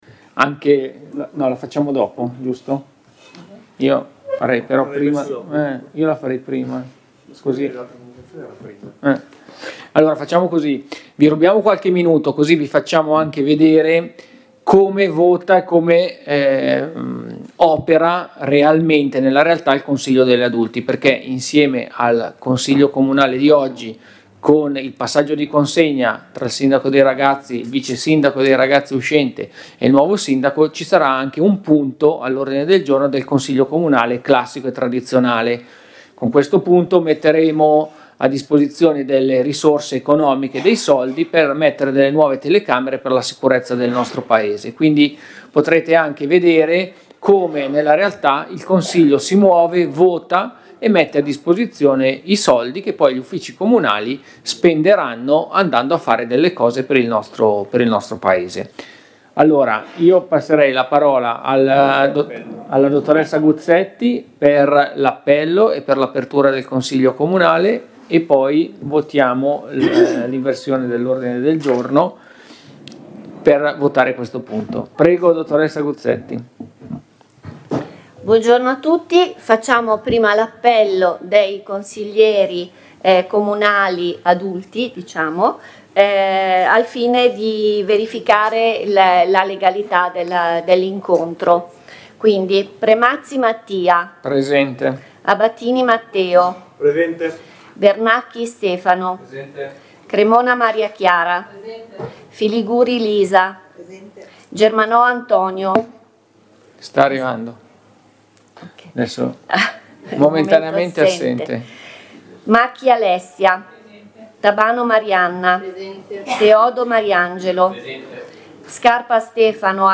Audio seduta Consiglio Comunale 18 febbraio 2026